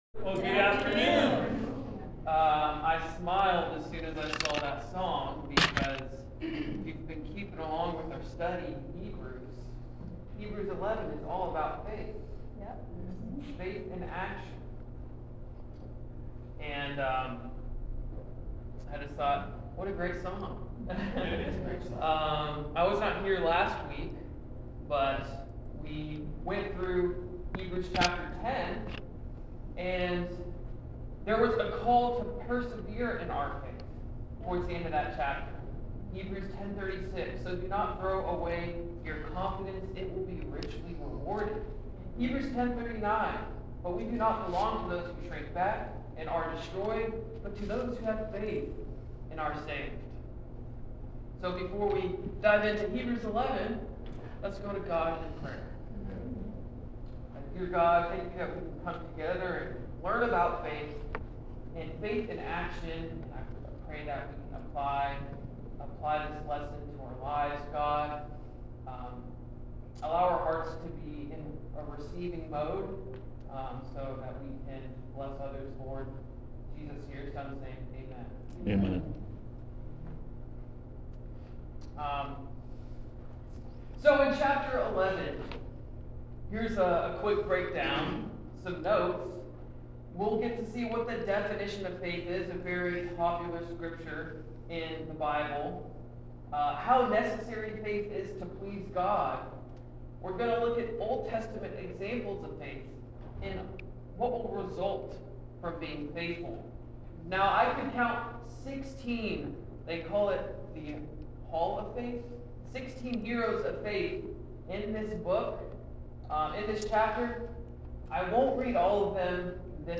Sermons | Tri-County Church